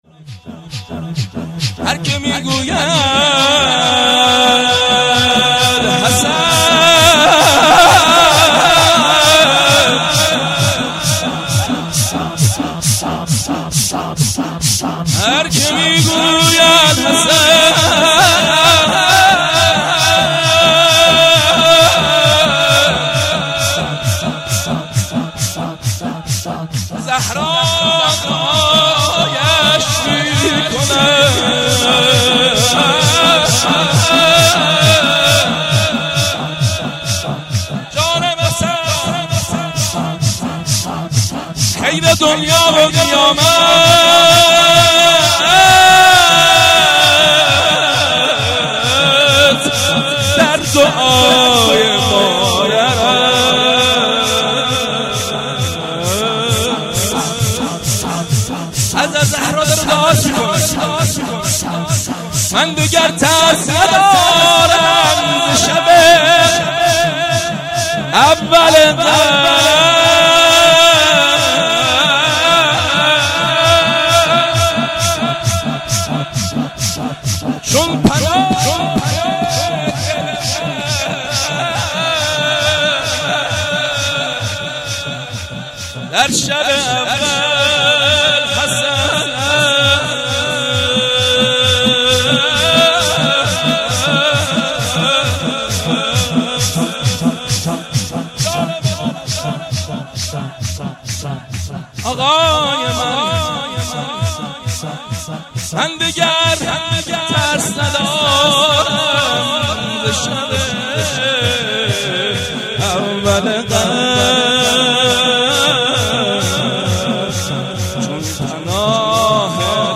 0 0 شور
جشن ولادت حضرت زهرا(س)- جمعه 18 اسفند